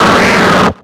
Cri d'Ossatueur dans Pokémon X et Y.